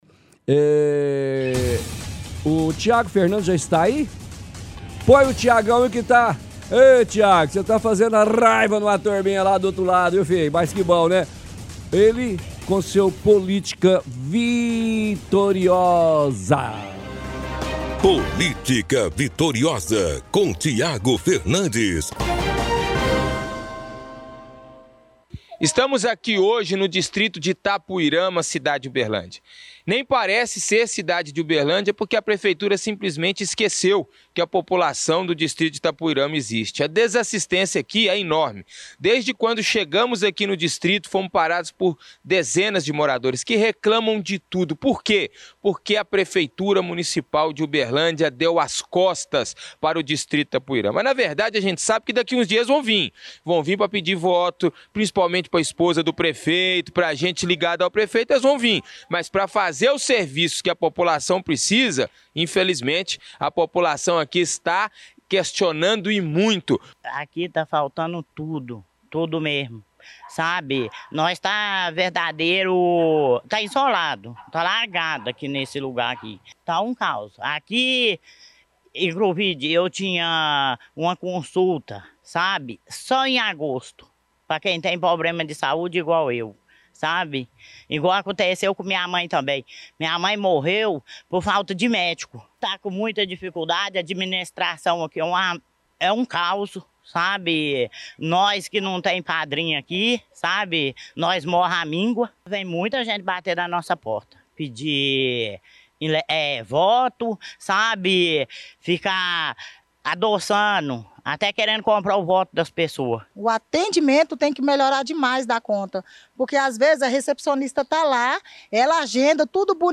– Transmissão de áudio da reportagem de hoje do chumbo Grosso.